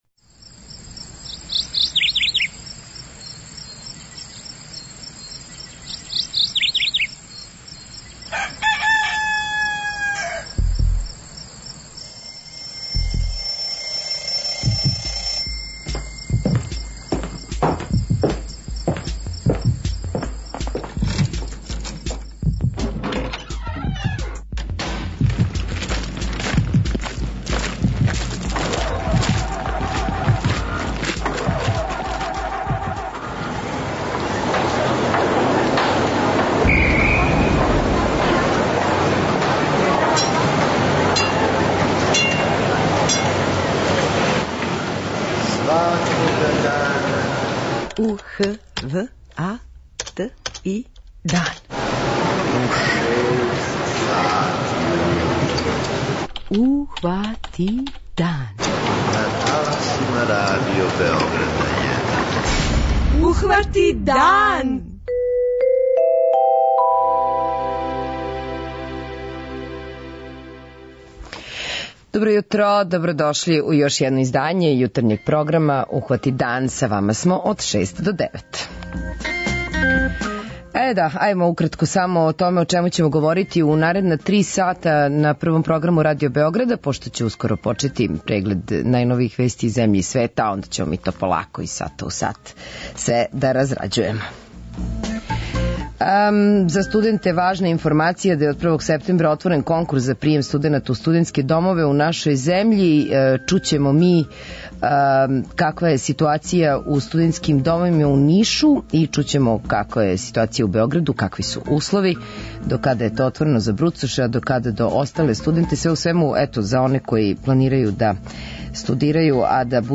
преузми : 43.16 MB Ухвати дан Autor: Група аутора Јутарњи програм Радио Београда 1!